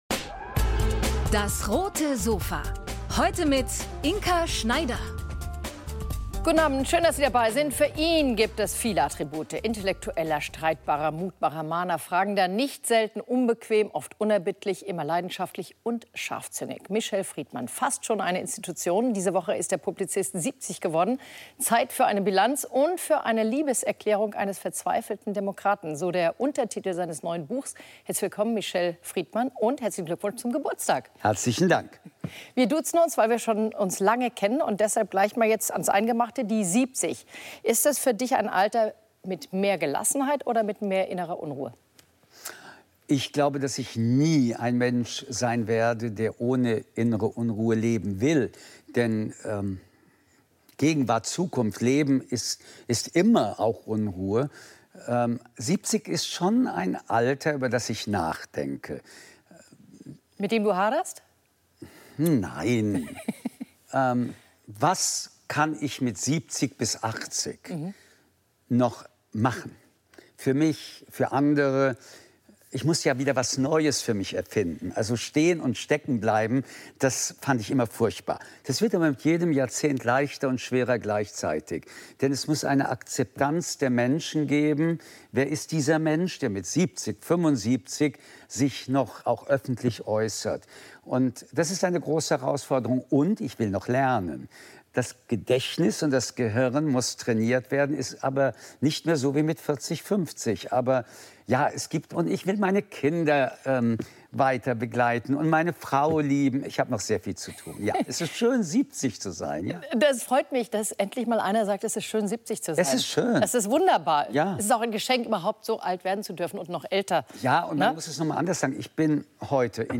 Wir freuen uns, eine der wichtigsten Stimmen Deutschlands mit einem eindringlichen Plädoyer für die Demokratie auf dem Roten Sofa begrüßen zu dürfen.